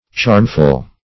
Charmful \Charm"ful\, a.
charmful.mp3